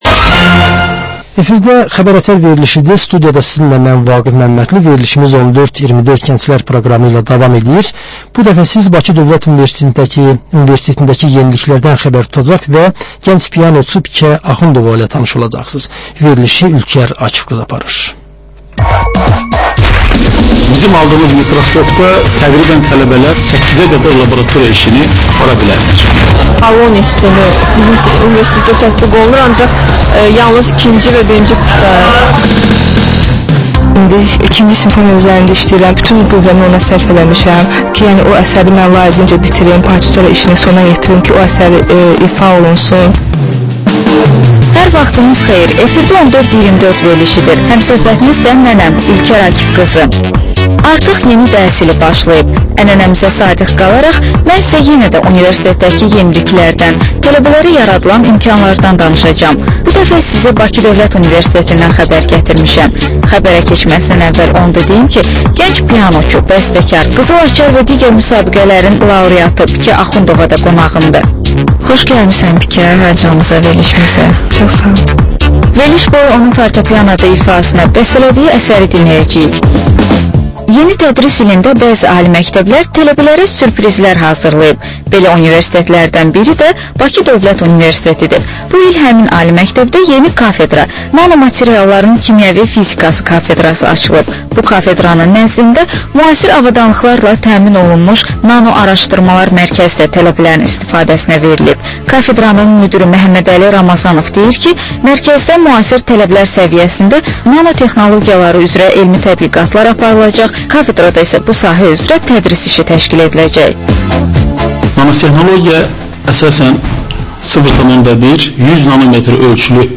Reportajç müsahibə, təhlil